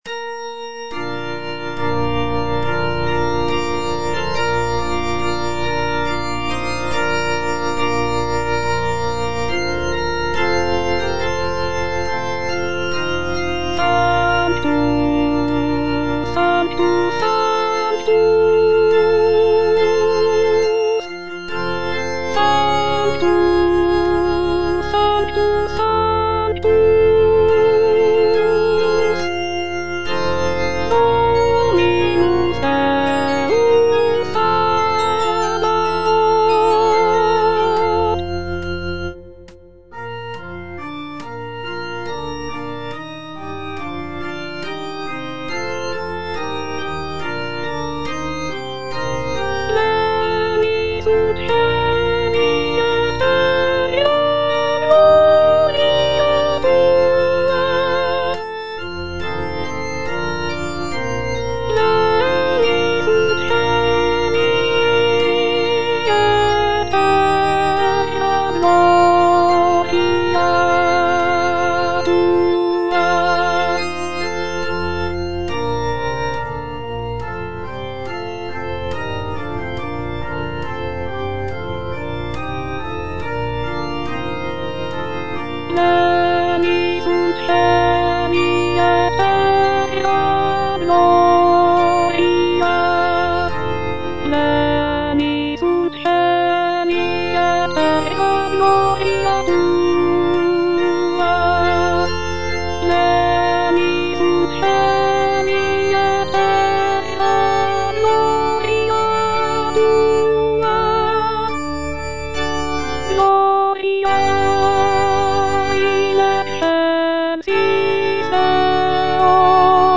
Alto (Voice with metronome) Ads stop
is a sacred choral work rooted in his Christian faith.